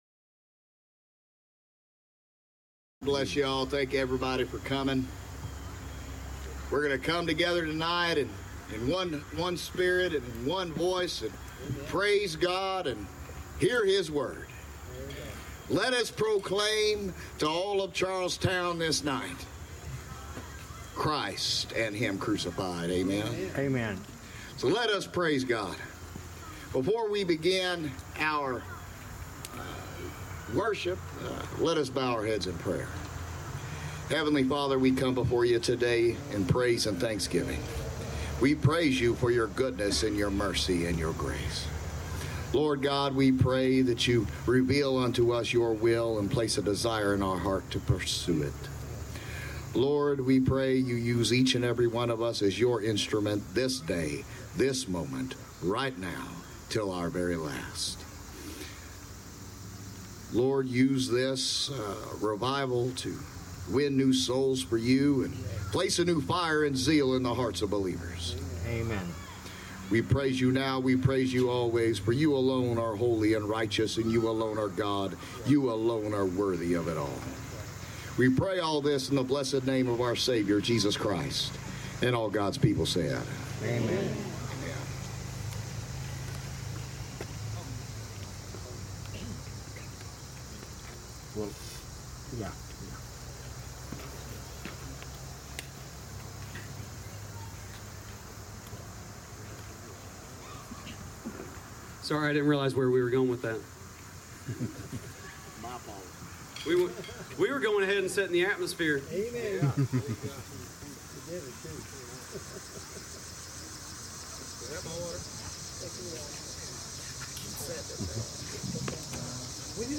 The Great Return September 11, with selected music and a Message on Forgiveness
Series: The Great Return Service Type: Revival Service